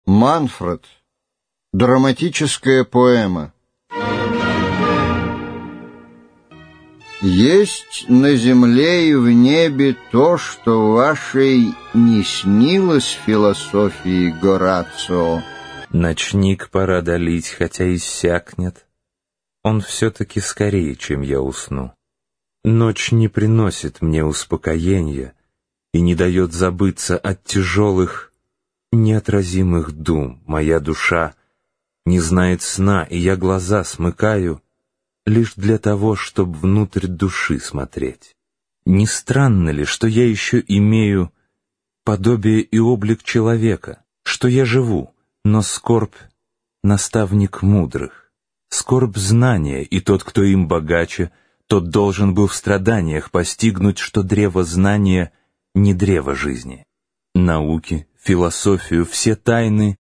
Аудиокнига Манфред | Библиотека аудиокниг